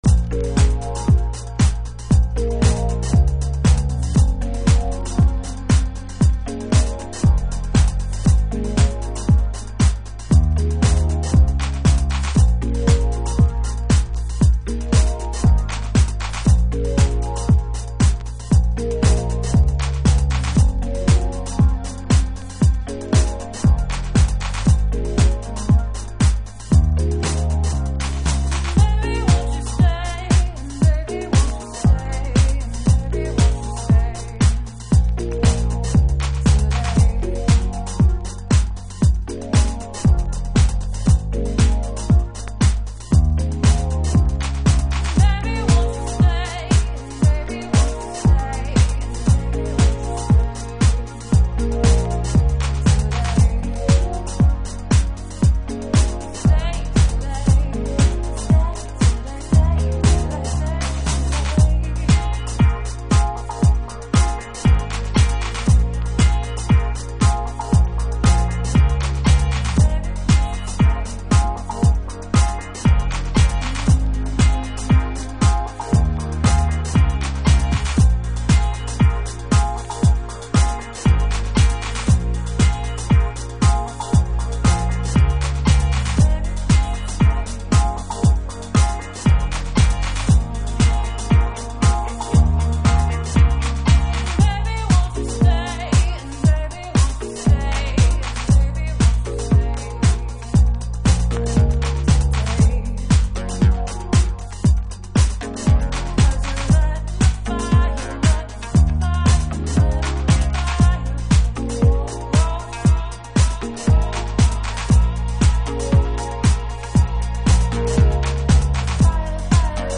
House / Techno
マシーンディスコが好きな方にも推薦できるグルーヴのトラックです。